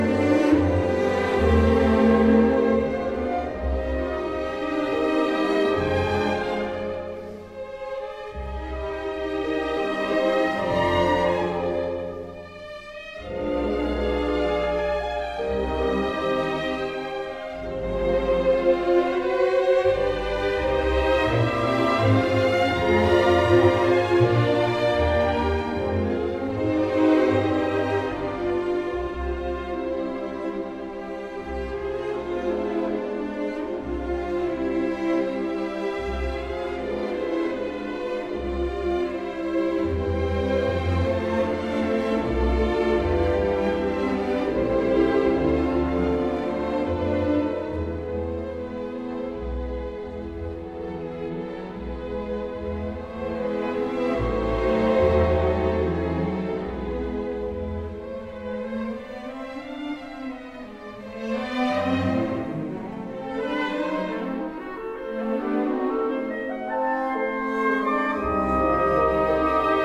Violin: Brahms: Symphony 3 mvt III (mm. 12-41) – Orchestra Excerpts
Sir Simon Rattle: Berlin Philharmonic, 2009